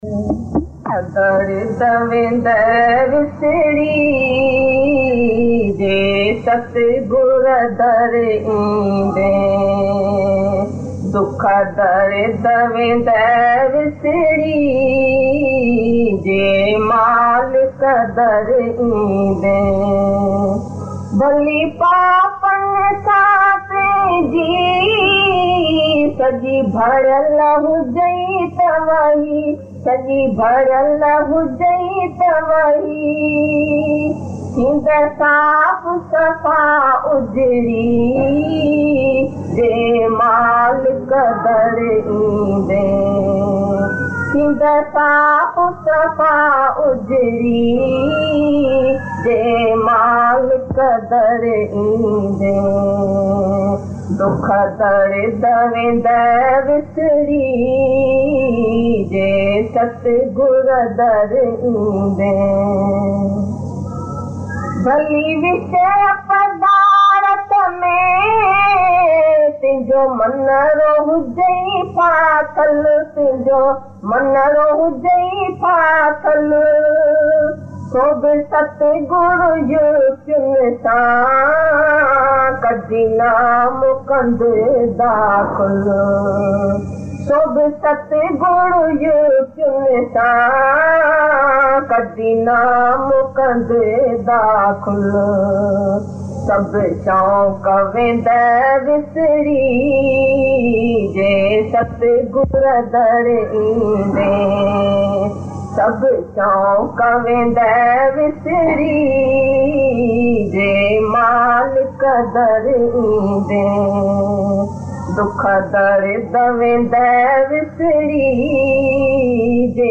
Dukh dard vendai visri Bhajan | दुख दर्द वेन्दई विसरी भजनDivine Geeta Bhagwan Hindi Bhajans